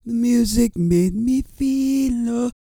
E-CROON 3011.wav